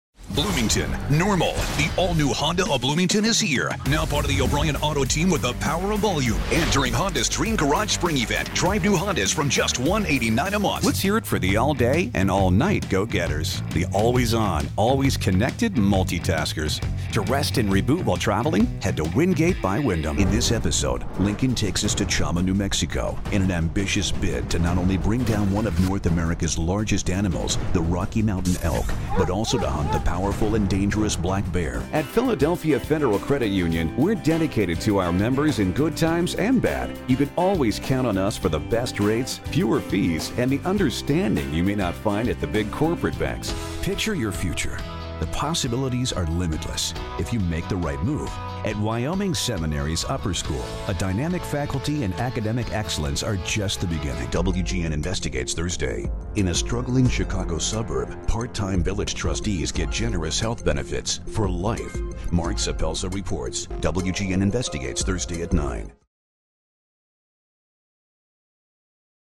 Commercials
Need to build some drama? Maybe you need a bit of folksiness? What about some refinement?